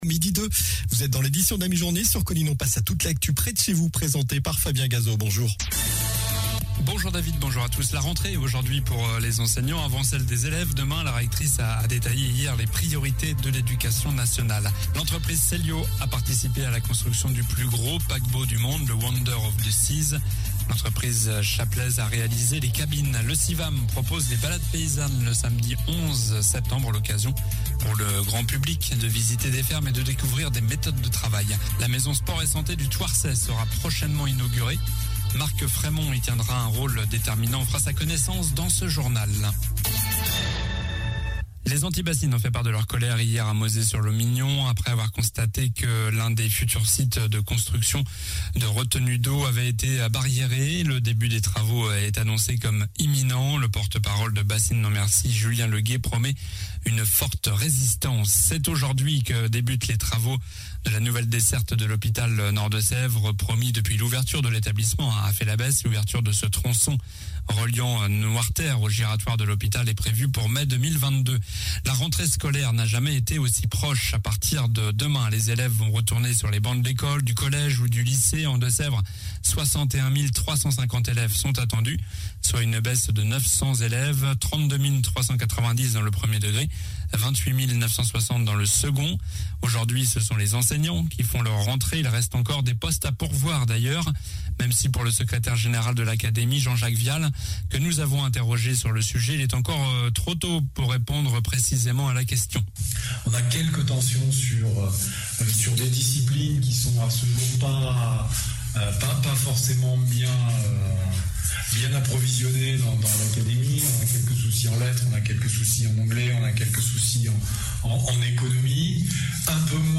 Journal du mercredi 1er septembre (midi)